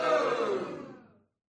Free Ambient sound effect: Party Crowd.
Party Crowd
396_party_crowd.mp3